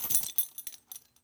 foley_keys_belt_metal_jingle_04.wav